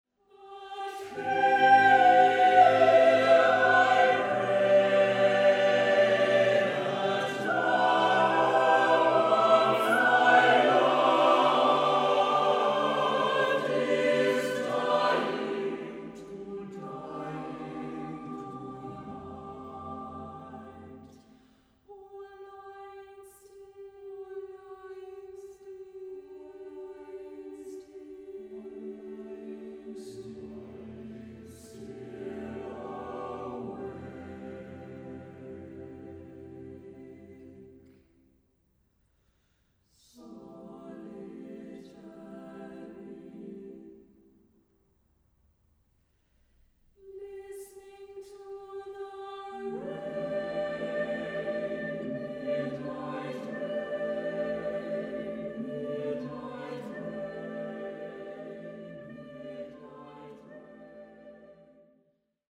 Features a baritone (or tenor) solo.